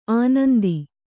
♪ ānandi